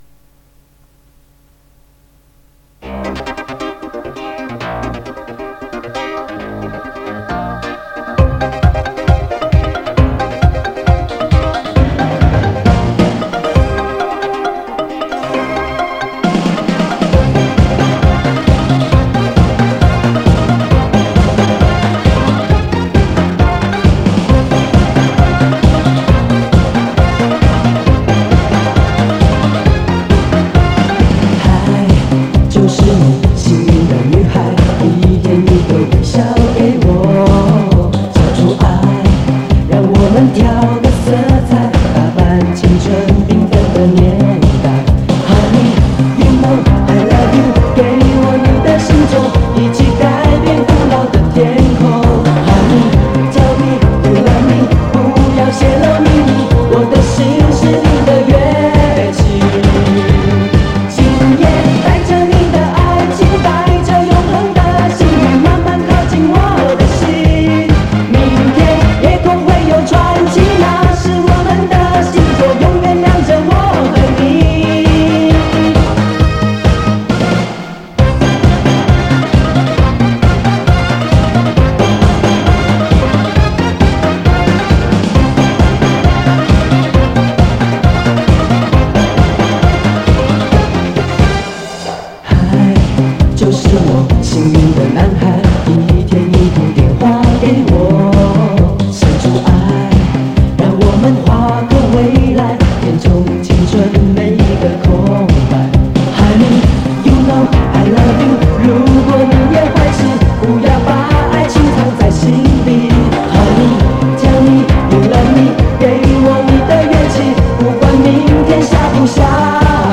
磁带数字化：2022-09-04